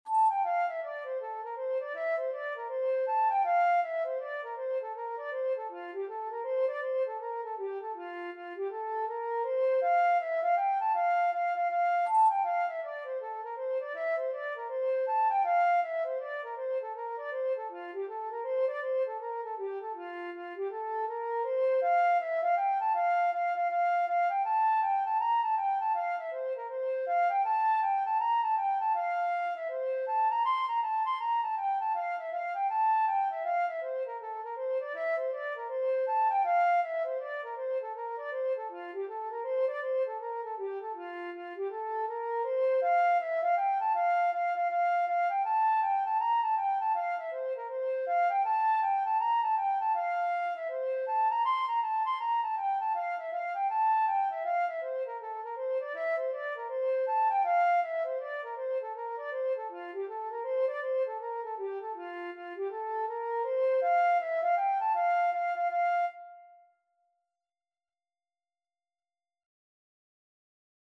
F major (Sounding Pitch) (View more F major Music for Flute )
4/4 (View more 4/4 Music)
F5-C7
Flute  (View more Easy Flute Music)
Traditional (View more Traditional Flute Music)